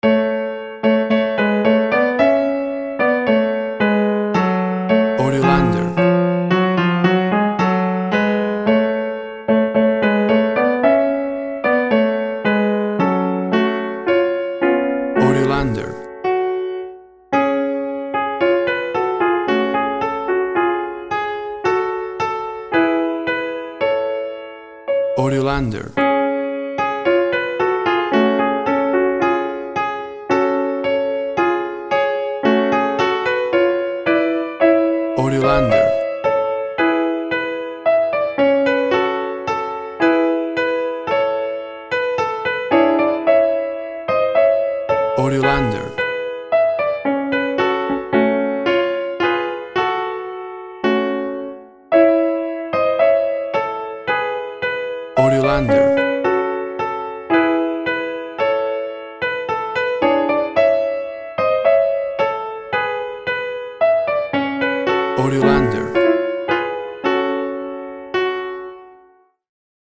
played on a Grand Piano
WAV Sample Rate 16-Bit Stereo, 44.1 kHz
Tempo (BPM) 110